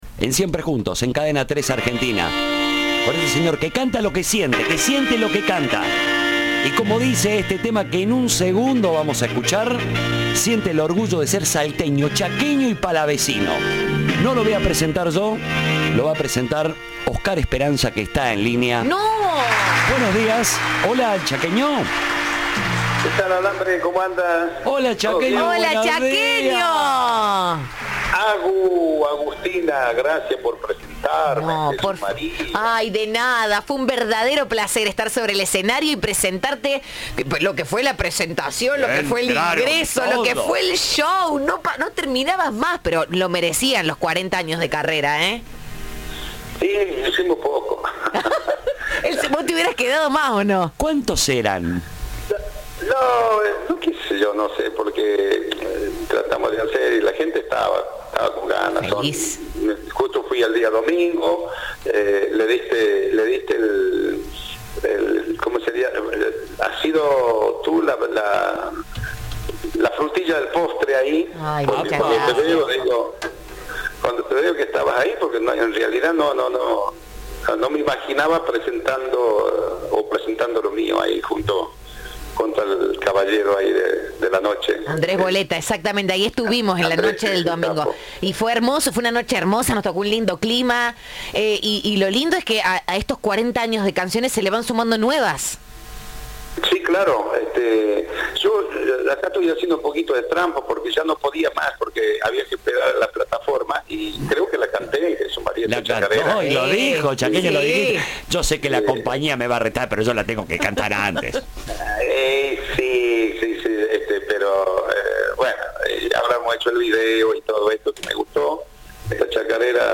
Luego de un exitoso paso por el Festival de Doma y Folklore de Jesús María, "El Chaqueño" Palavecino estrenó de forma exclusiva en Siempre Juntos su nueva canción "La Palavecino".
Entrevista